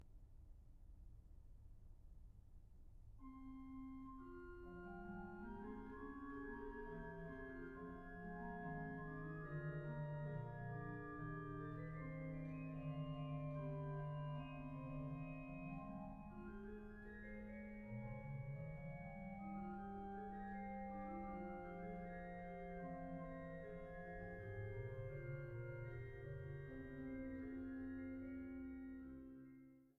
Rieger Orgel der St. Katharinenkirche Frankfurt am Main
Orgel